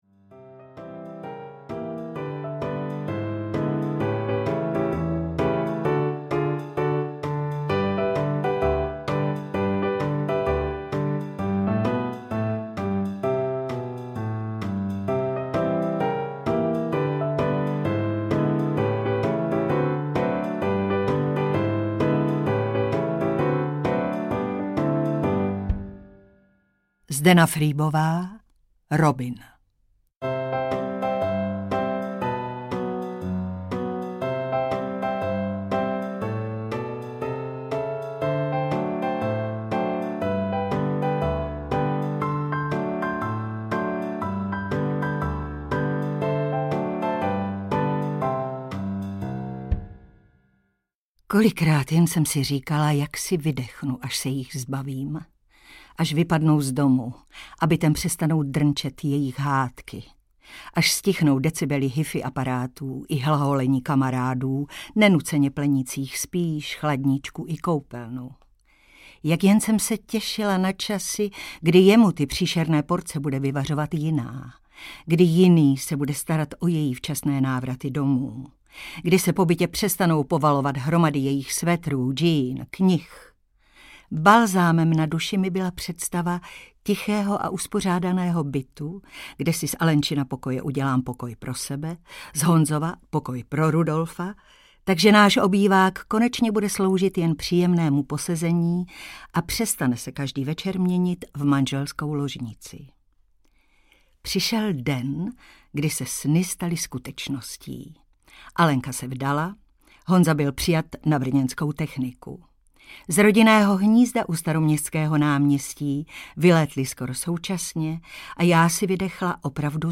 Robin audiokniha
Ukázka z knihy
• InterpretHana Maciuchová